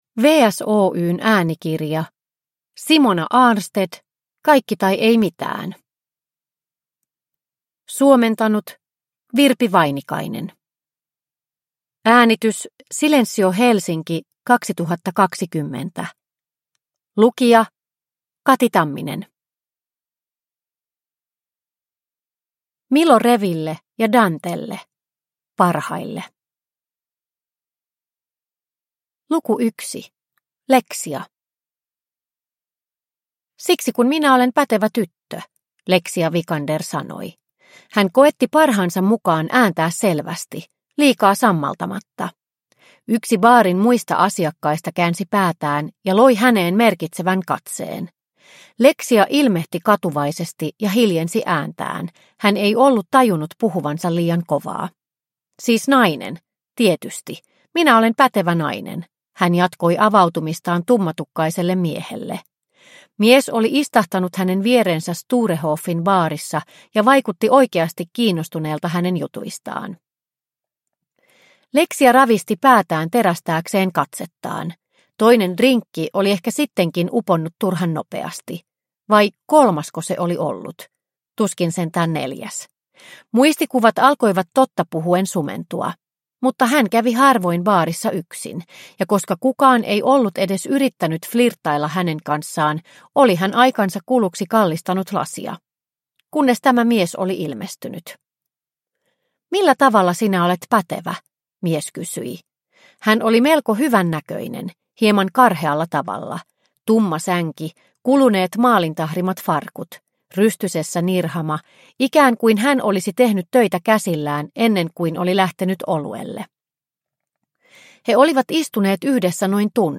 Kaikki tai ei mitään – Ljudbok – Laddas ner